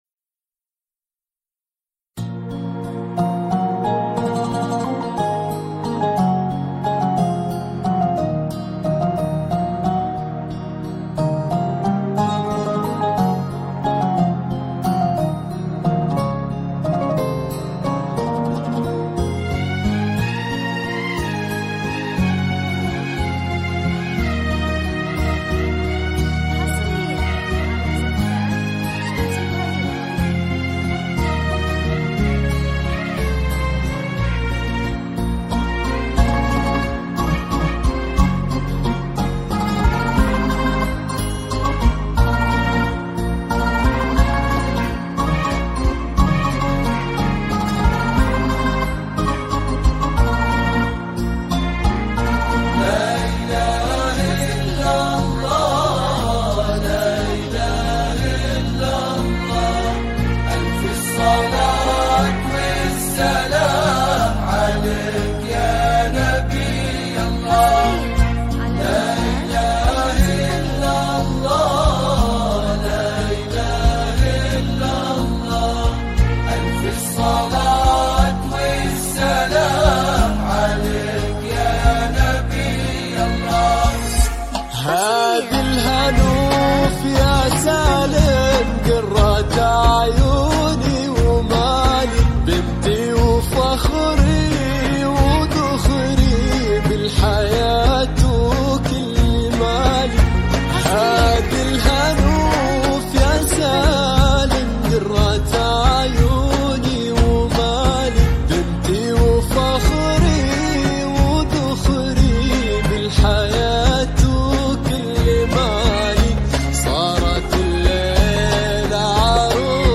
زفات موسيقى – زفات كوشة